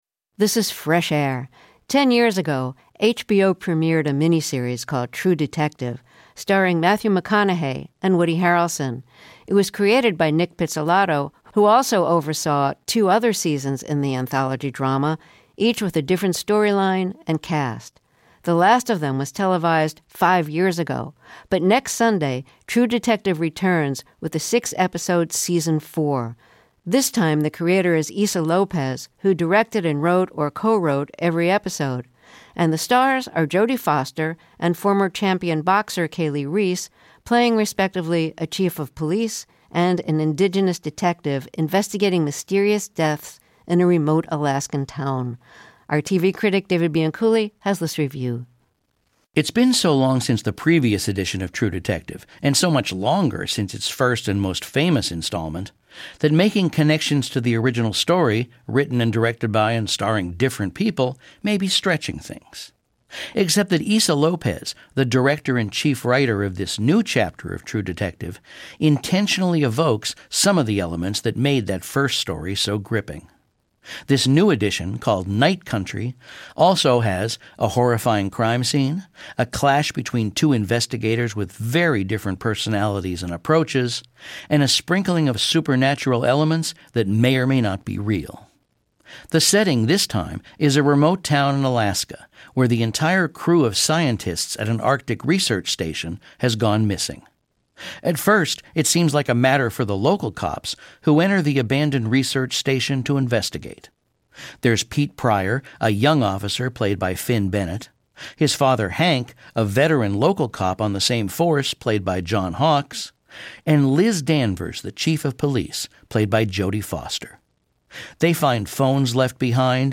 TV Review